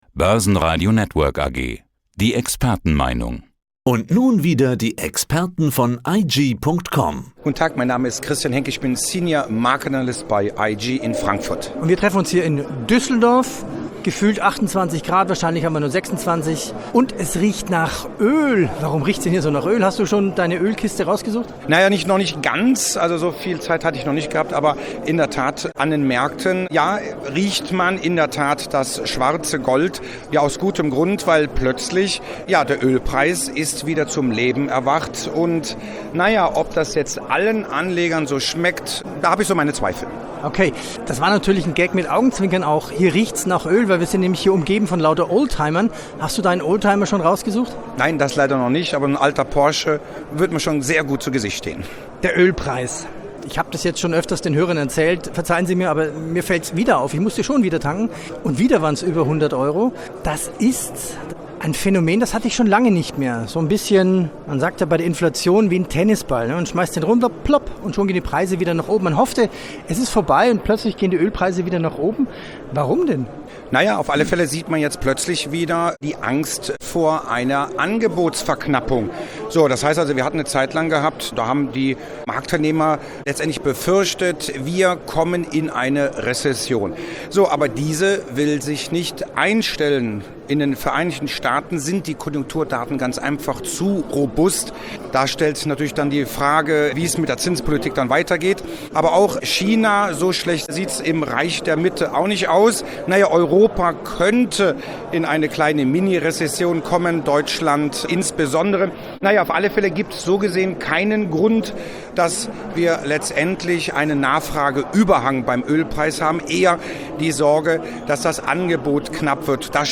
"Schnauferlparade" auf dem Börsentag in Düsseldorf.